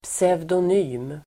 Ladda ner uttalet
Uttal: [psevdon'y:m]